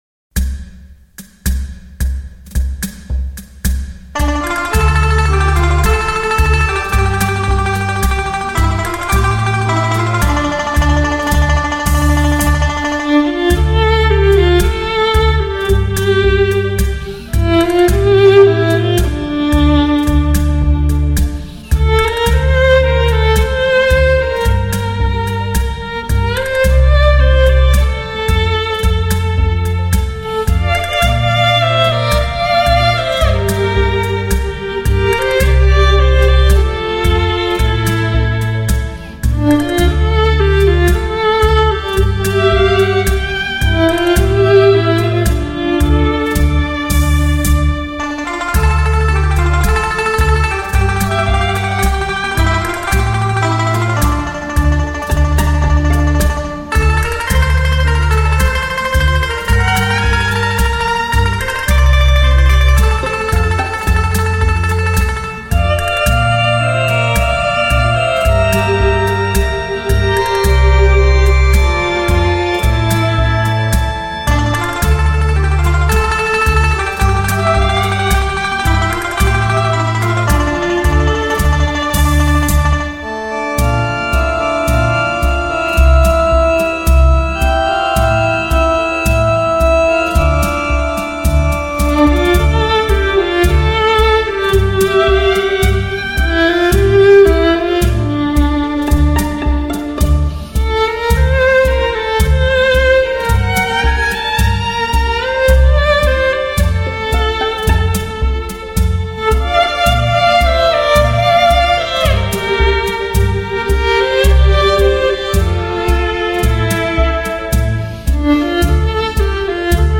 整个专辑听起来特别悠扬、婉转，给人的内心带来了难得的安宁，同时也让人生出些许伤感，特
为低音质MP3